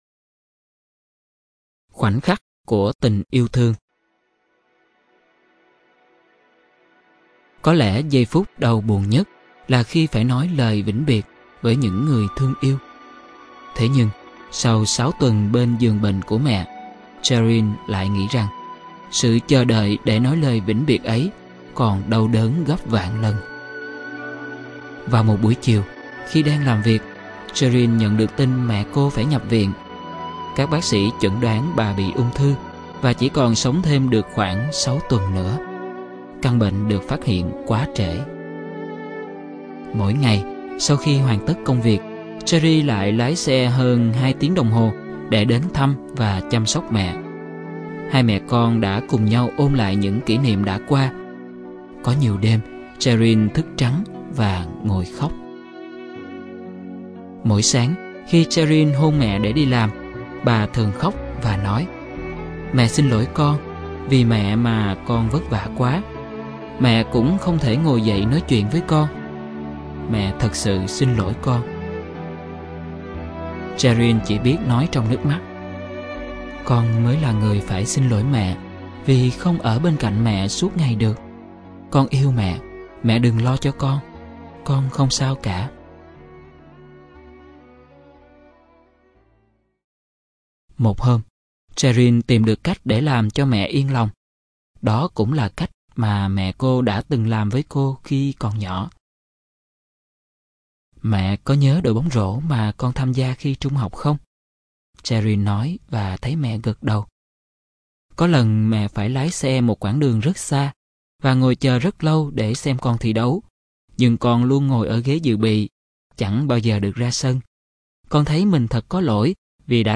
Người đọc